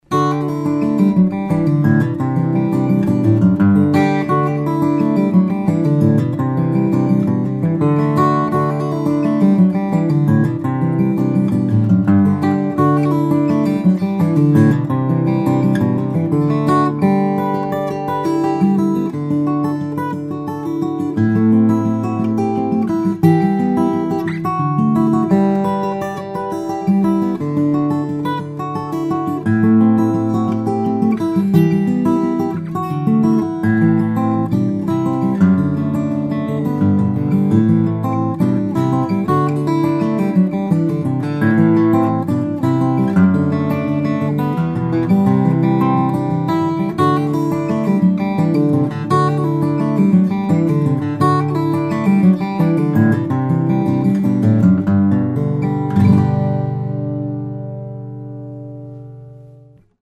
This one has a very strong and powerful voice with trebles strings that are warm and round.